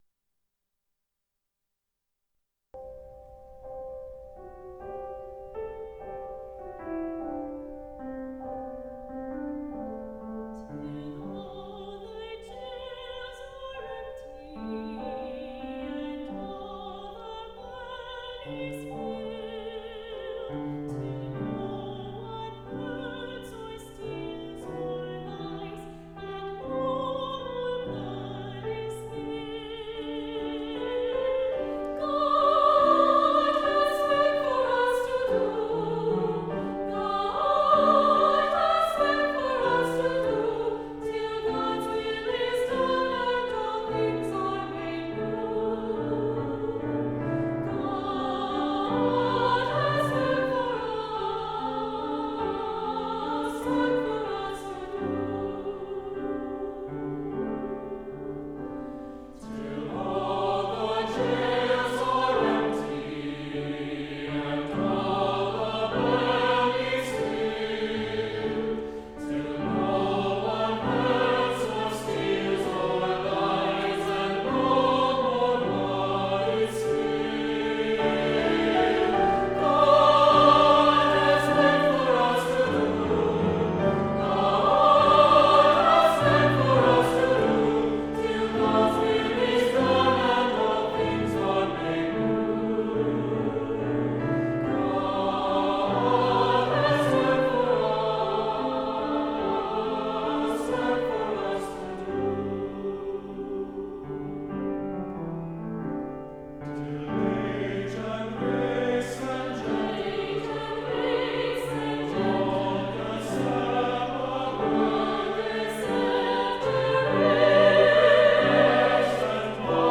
CHANCEL CHOIR